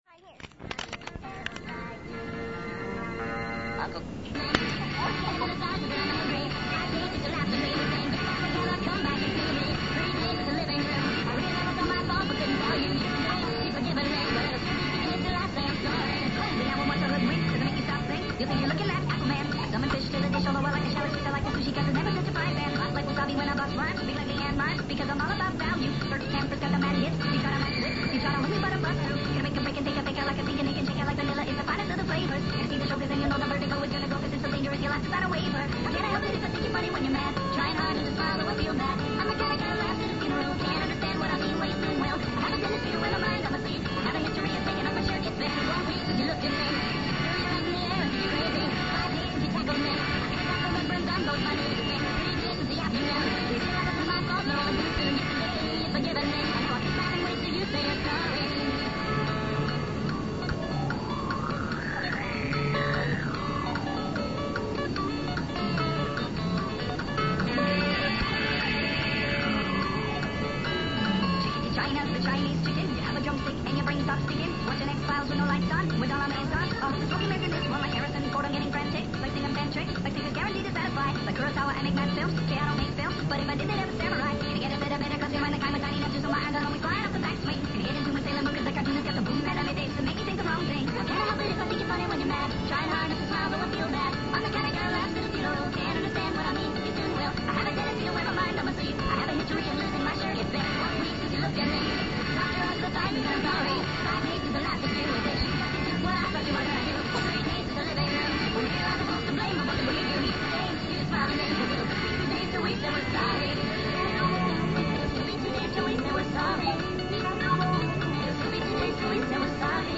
Know of a song you want to hear chipmunk-ized?